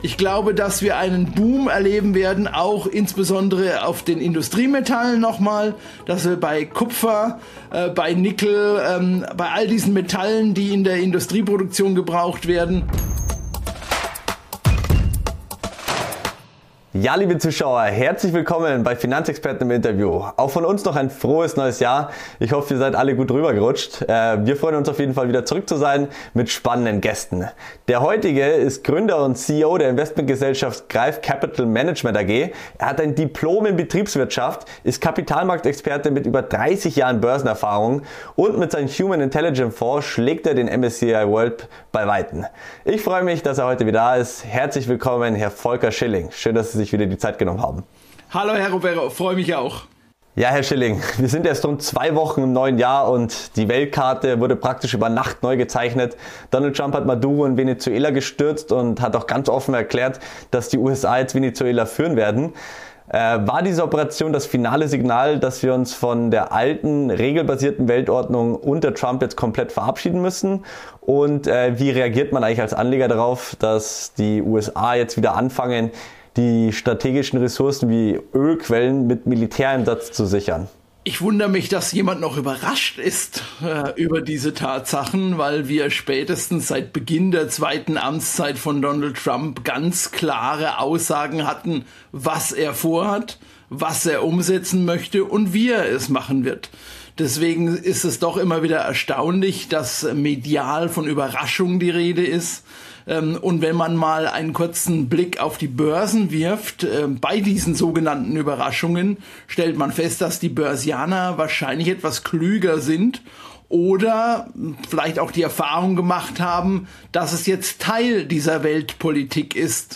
Ein Interview voller klarer Thesen, historischer Vergleiche und konkreter Einordnungen für alle, die verstehen wollen, was an den Märkten wirklich zählt.